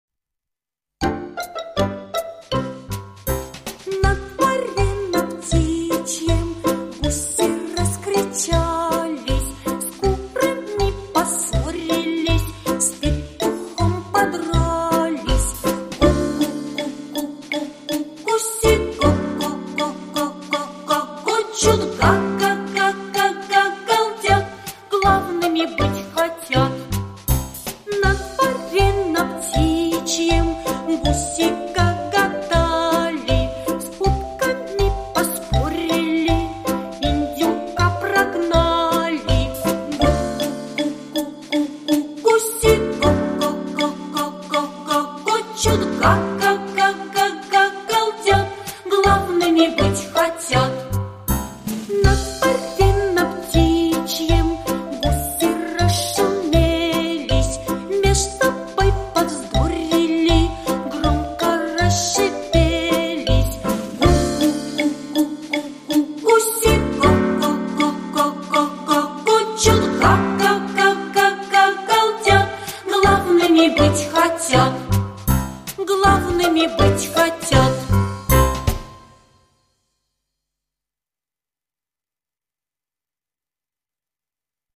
Гуси - песенка про животных - слушать онлайн